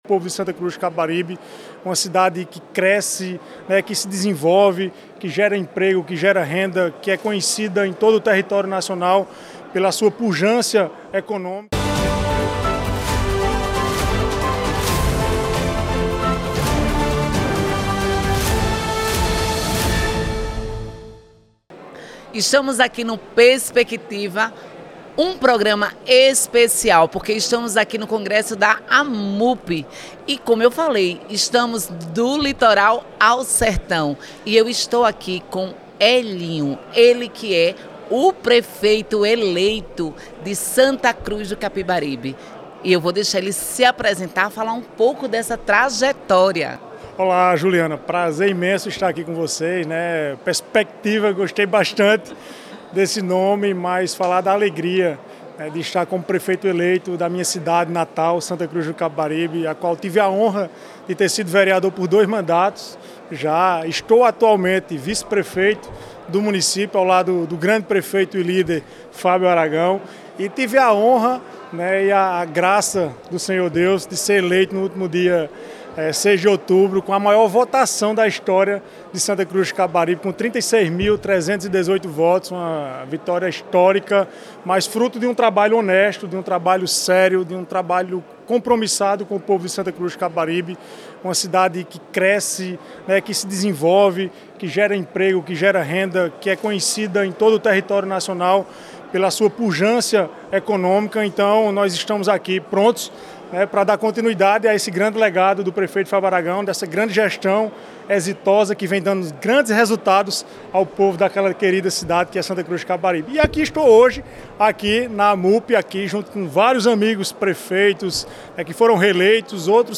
Em entrevista para o Programa Perspectiva , da Rede Você, ressalta o grande boom de desenvolvimento do município , com a força econômica do polo têxtil , um dos maiores do país.